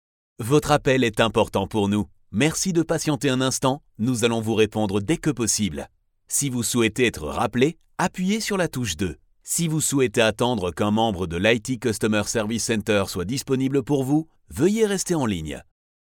French Male Voice Over Artist
Confident, Corporate, Natural, Reassuring, Warm
Audio equipment: StudioBricks booth, RME Babyface interface, CAD EQuitek E100S mic